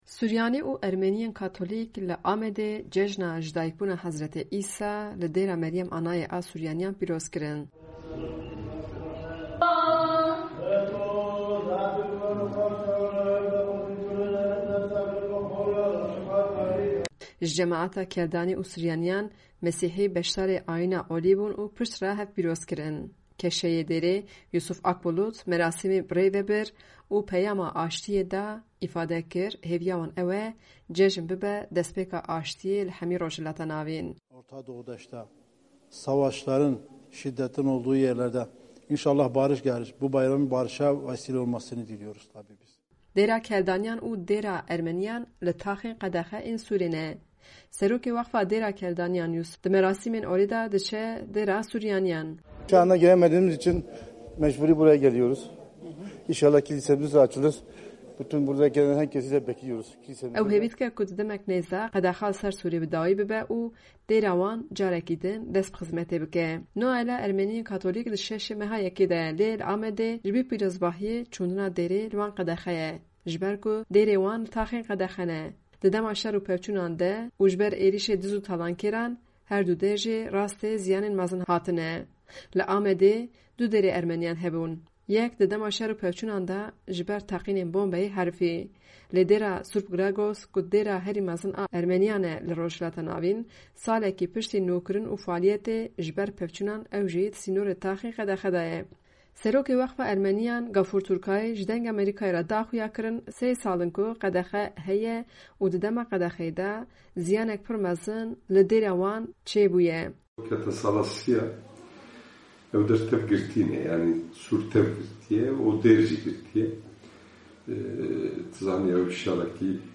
Cejna dayikbûna Hezretî Îsa, li Amedê li dêra Dayik Meryem ya Suryanîyan hat pîroz kirin.